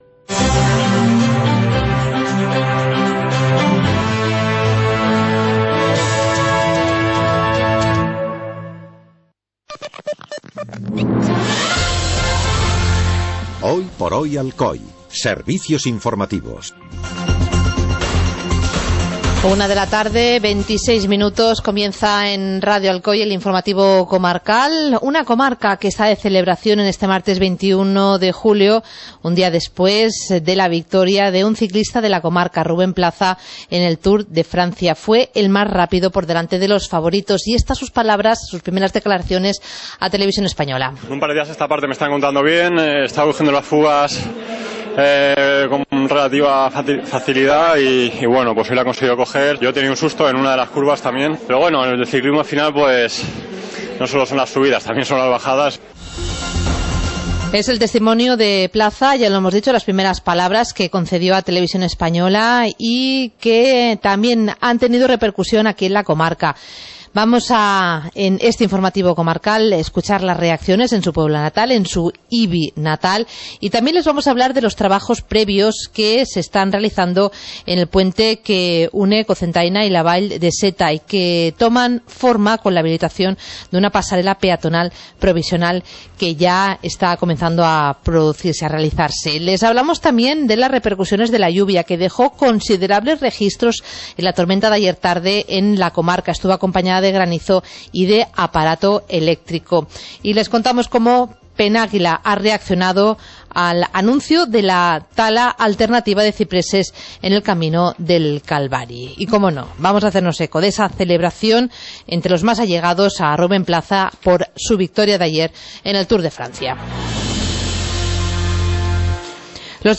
Informativo comarcal - martes, 21 de julio de 2015